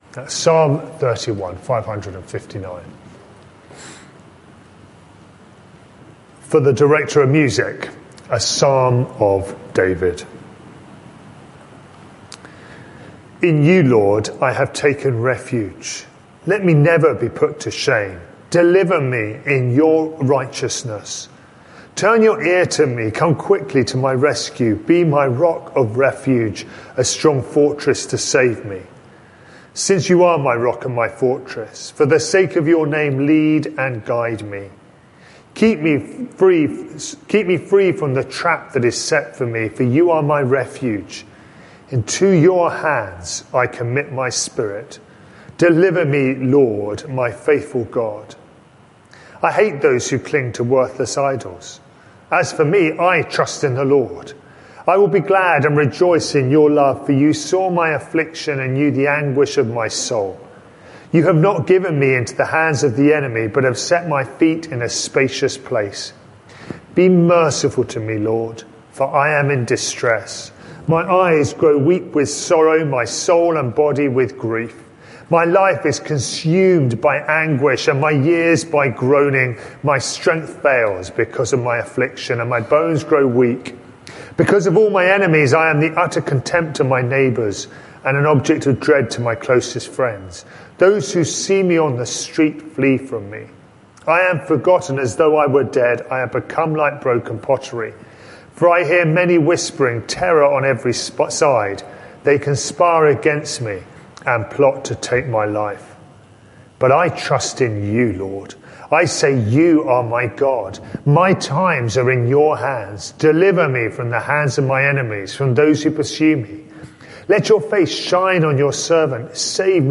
This sermon is part of a series: 26 March 2023